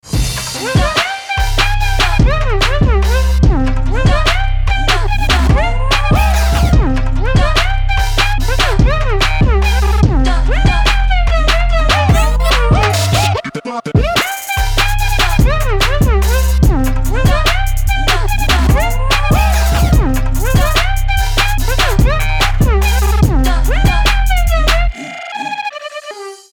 Веселый трэп